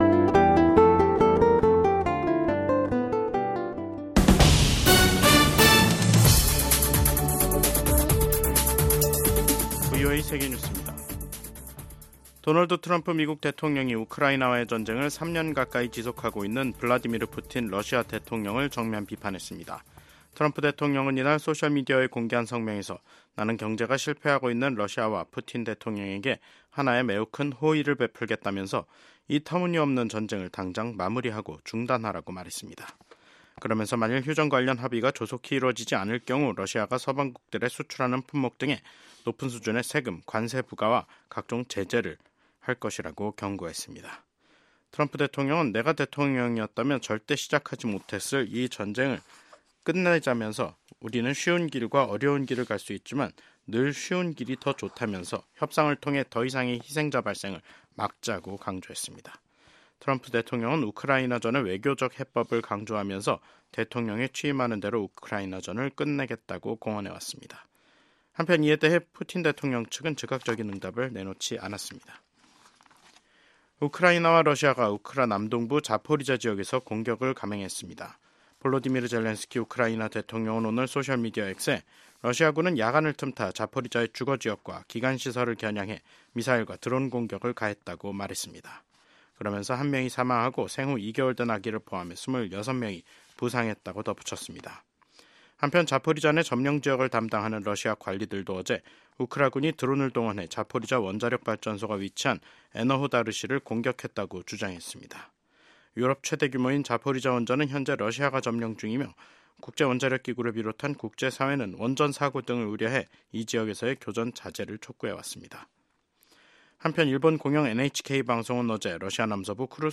VOA 한국어 간판 뉴스 프로그램 '뉴스 투데이', 2025년 1월 23일 2부 방송입니다. 미국 공화당 의원들은 도널드 트럼프 대통령의 두 번째 임기를 환영하며, 북한과 중국, 러시아, 이란 등 독재국가들에 대한 강경 대응을 예고했습니다. 마르코 루비오 미국 국무장관은 조태열 한국 외교부 장관은 도널드 트럼프 대통령 취임 사흘날 첫 전화 통화를 하고 북핵 문제에 대해 긴밀한 공조를 유지하기로 했습니다.